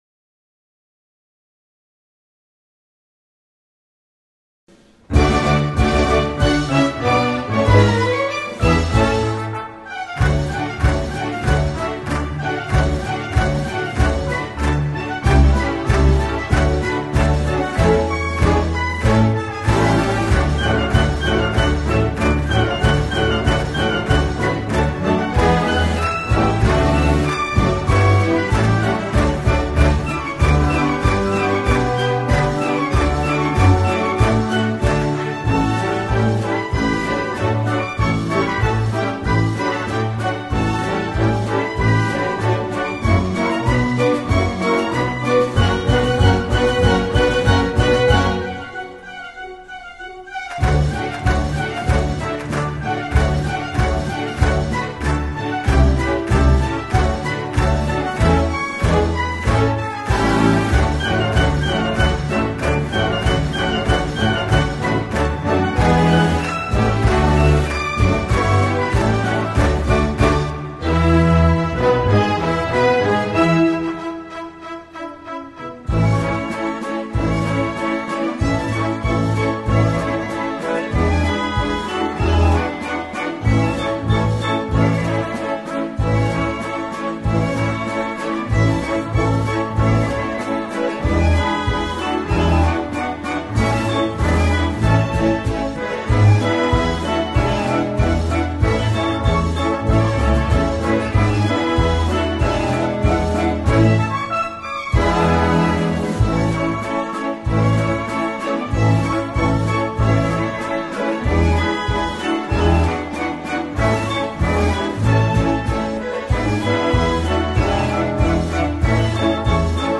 02:38:00   Clássica